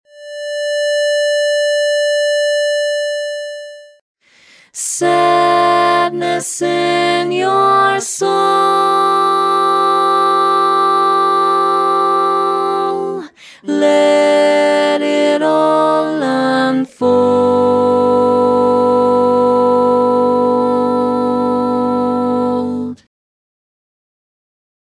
Key written in: D Minor
Learning tracks sung by